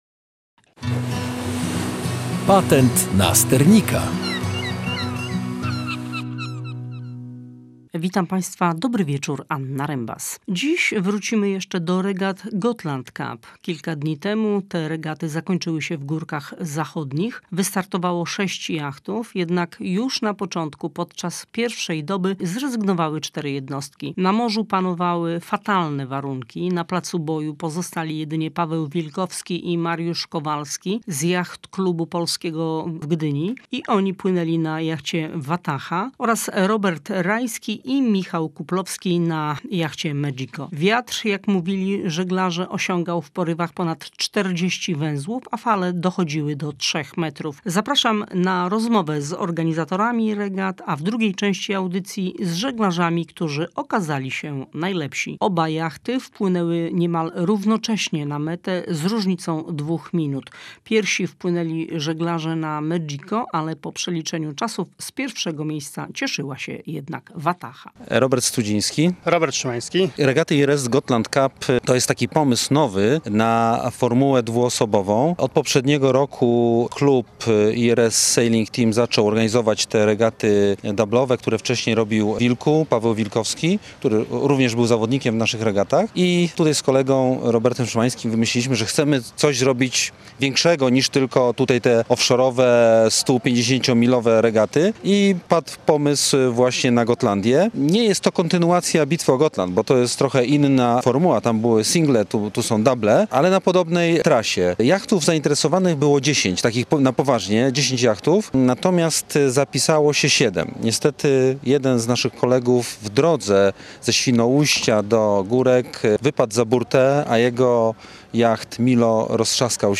Rozmowa z organizatorami i zwycięzcami Gotland Cup, czyli najtrudniejszych regat na Bałtyku